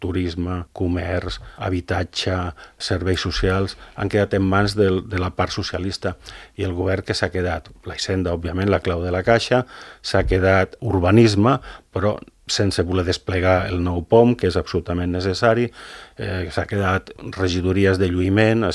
El portaveu de Calella en Comú Podem ha fet balanç dels dos anys de mandat municipal a l’entrevista política de Ràdio Calella TV d’aquesta setmana.